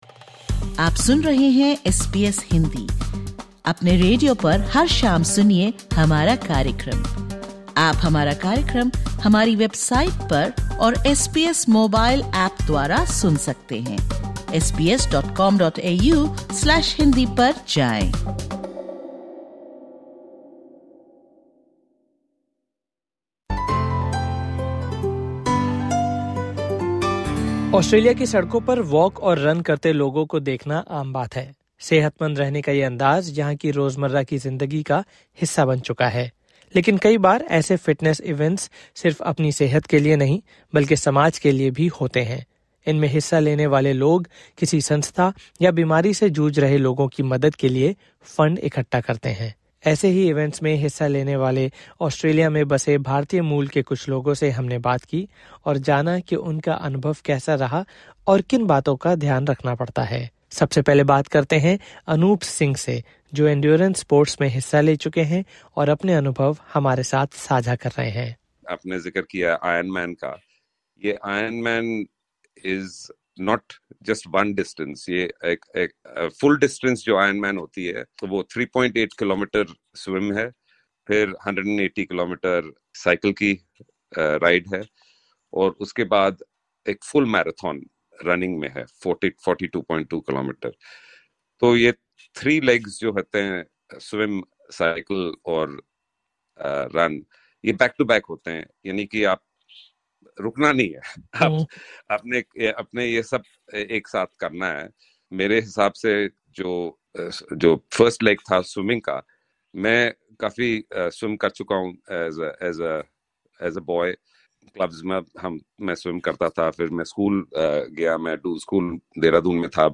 In this SBS Hindi podcast, members of the Indian-origin community in Australia share how their participation in walks, runs, and marathons goes beyond fitness — becoming a way to support important causes. We hear from three individuals who encourage others to take the first step and get involved.